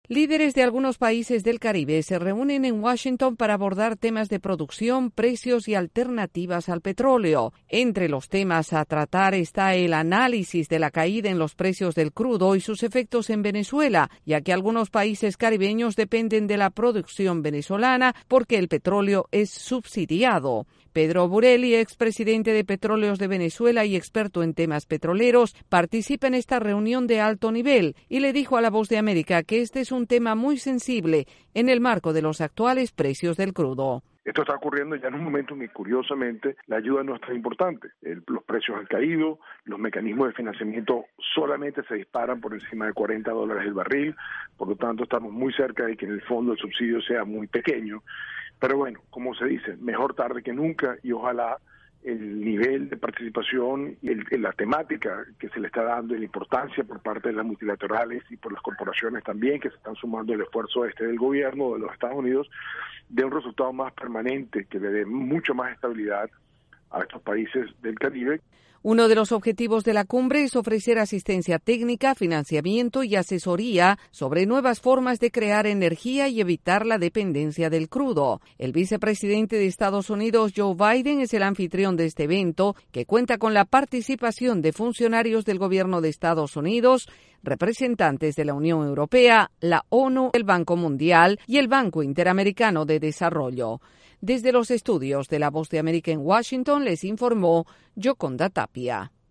Estados Unidos y las naciones del Caribe analizan alternativas para enfrentar la disminución en el precio del petróleo. Desde la Voz de América en Washington DC informa